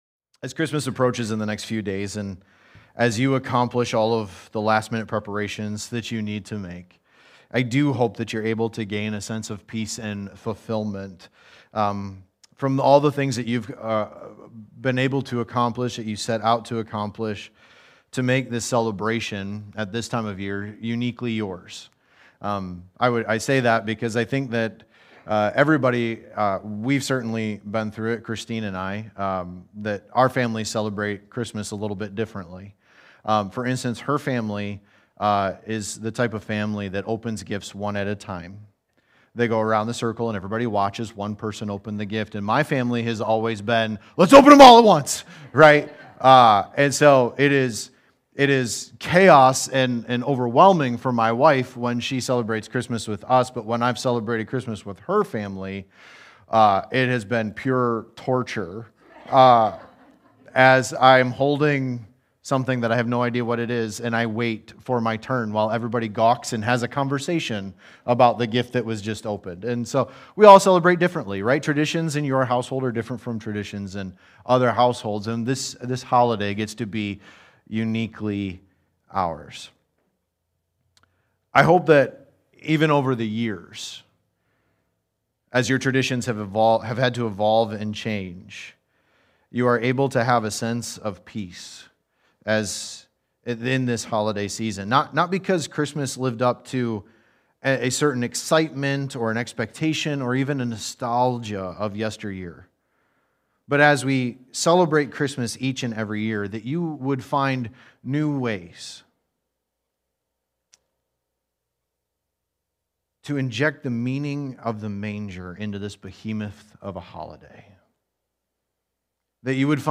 Sermons | The Rising Christian Church